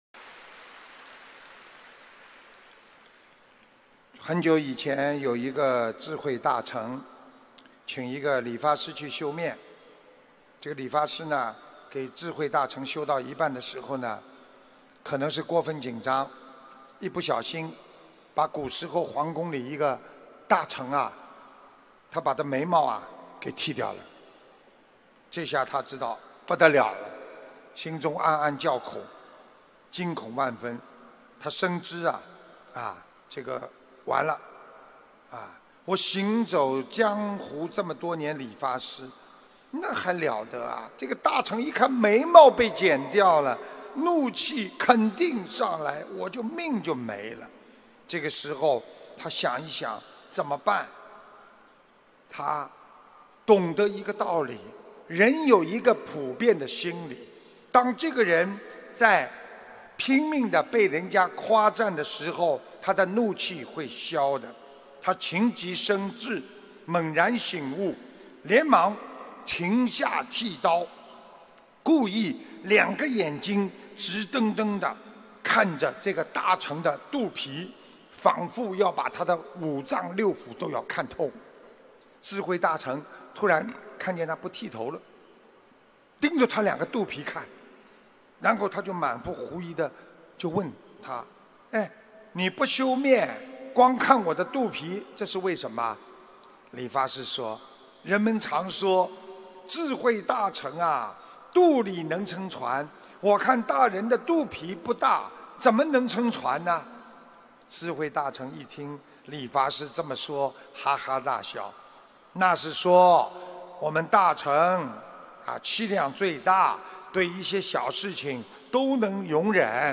音频：学会智慧妙语 口吐莲花 可消解怨气·师父讲小故事大道理